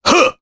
Crow-Vox_Attack1_jp.wav